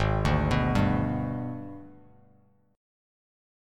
AbmM7b5 chord